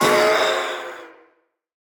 Minecraft Version Minecraft Version 1.21.5 Latest Release | Latest Snapshot 1.21.5 / assets / minecraft / sounds / mob / phantom / death3.ogg Compare With Compare With Latest Release | Latest Snapshot